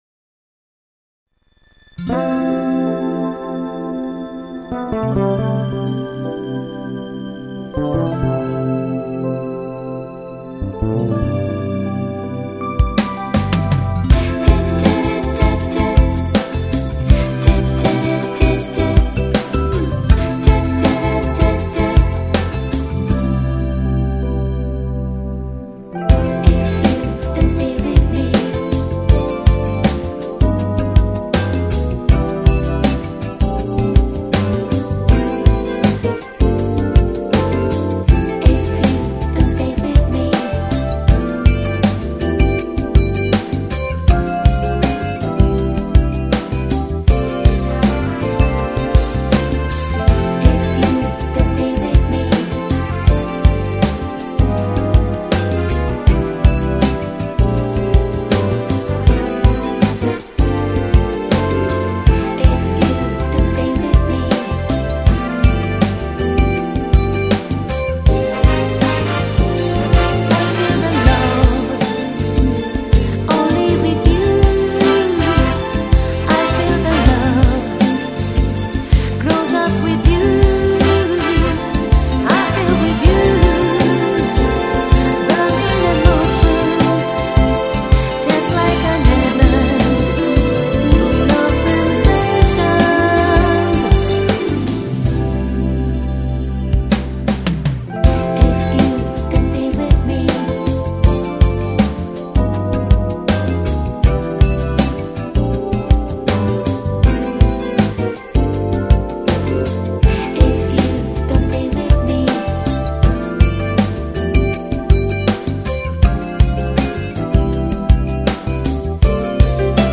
Genere: Lento
Scarica la Base Mp3 (3,36 MB)